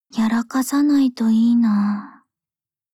Cv-40803_warcry.mp3